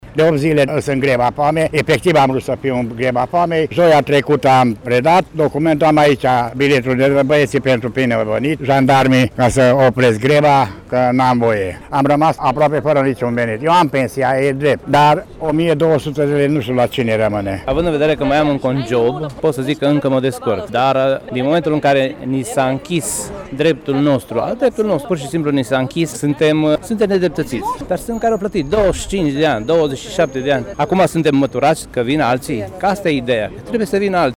Comercianții de la Platoul Cornești s-au adunat astăzi, la orele amiezii, în fața Primăriei municipiului Târgu-Mureș, pentru a își exprima nemulțumirile după ce activitatea acestora a fost sistată spontan.
Comercianții se consideră nedreptățiți și susțin că au fost înlăturați de la Platou pentru a se face loc altor persoane. Unul dintre aceștia a vrut chiar să intre în greva foamei: